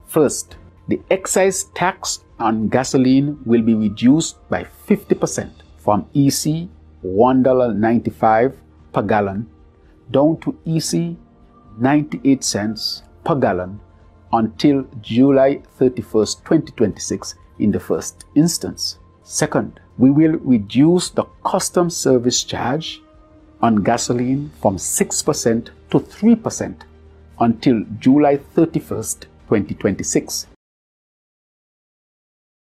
Prime Minister, the Hon. Dr. Terrance Drew, announced in a national address, relief measures to ease the cost of living for citizens and residents across St. Kitts & Nevis.